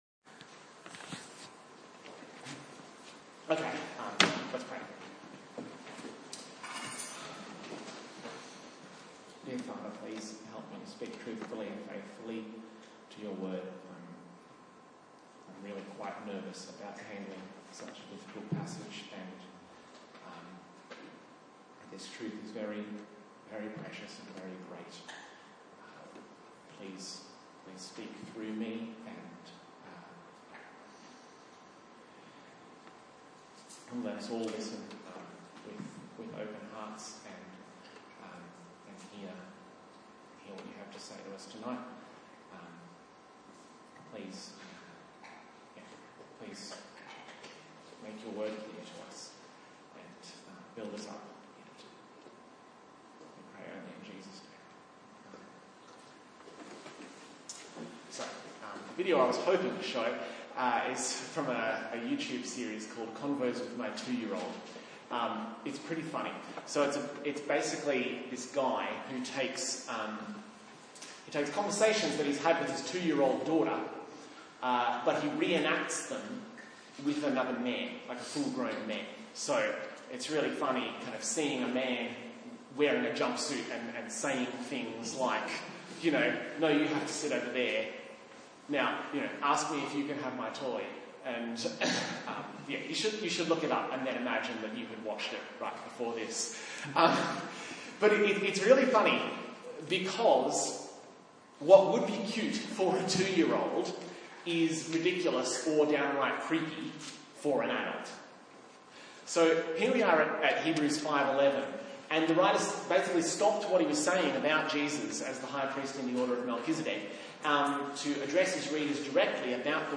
A sermon in the series on the book of Hebrews